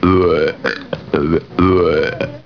1burp
1burp.wav